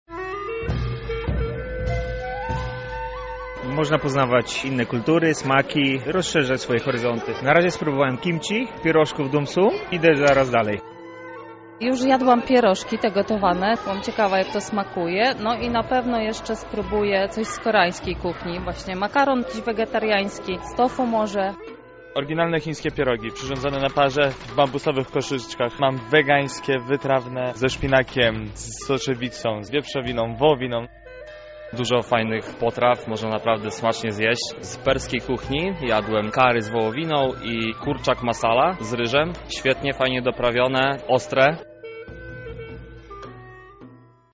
Marynowana rzepa, chaczapuri i chińskie pierożki. W Lublinie odbył się Festiwal Azjatycki